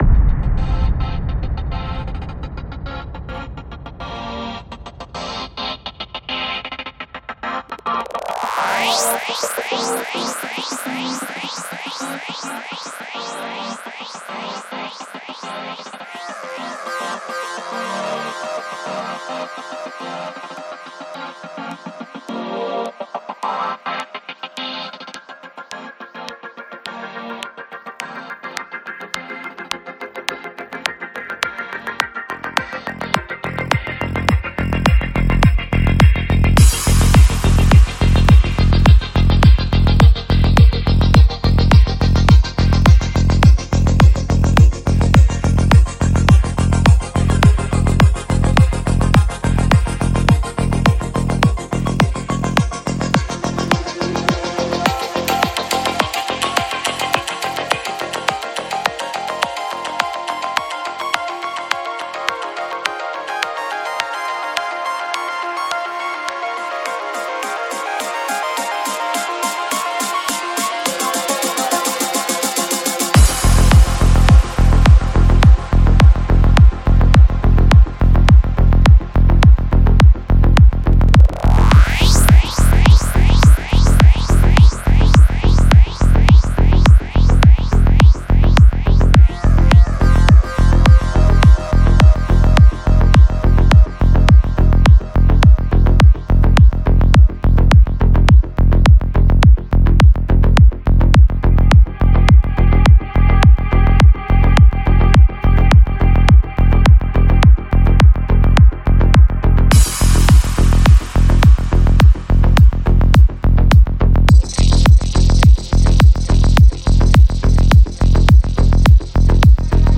Жанр: Psychedelic